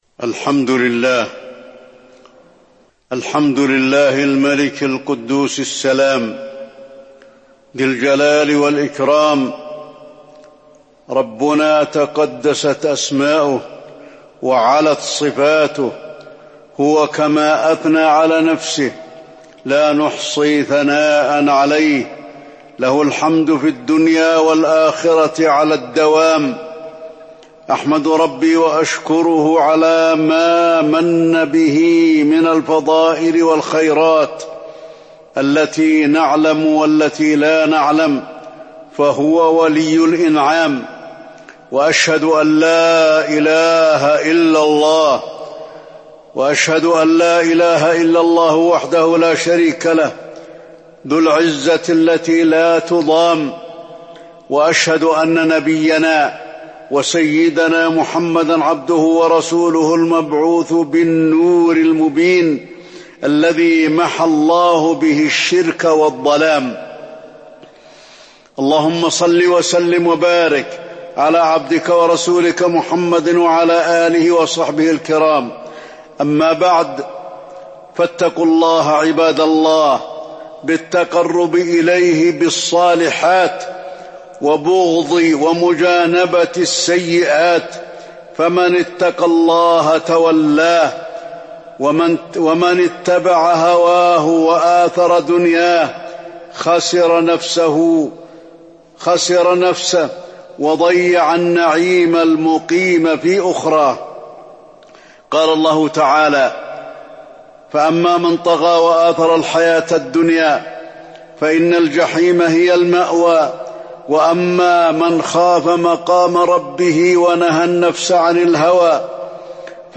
تاريخ النشر ١٢ محرم ١٤٤٣ هـ المكان: المسجد النبوي الشيخ: فضيلة الشيخ د. علي بن عبدالرحمن الحذيفي فضيلة الشيخ د. علي بن عبدالرحمن الحذيفي فضل ذكر الله